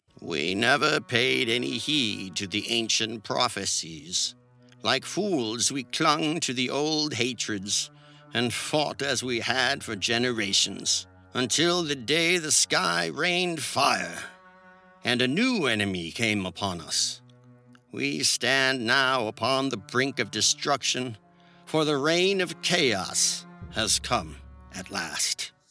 Animation Voiceover
• Broadcast-quality audio